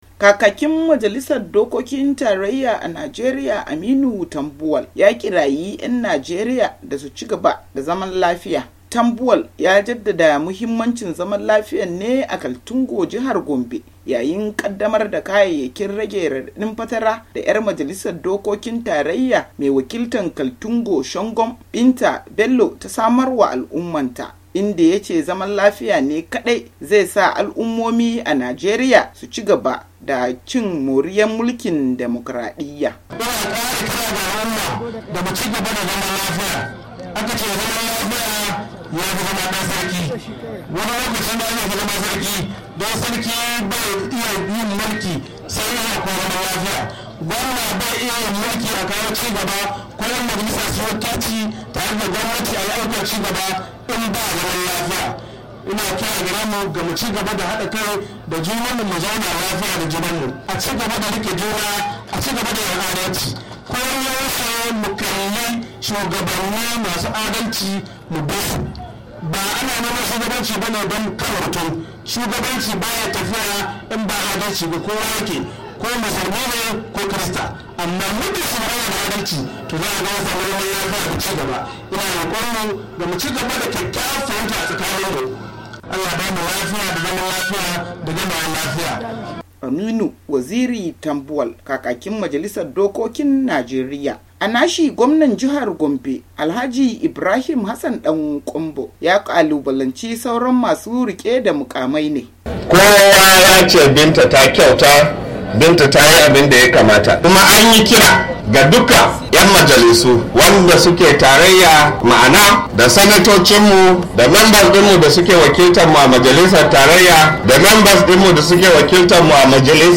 Yayin da yake jawabi wajen raba kayan rage radadin fatara da wata 'yar majalisar wakilan tarayya Binta Bello ta samarma al'ummarta a Kaltungo, Aminu Waziri Tambuwal kakakin majalisar wakilan Najeriya ya kira 'yan Najeriya da su cigaba da zaman lafiya.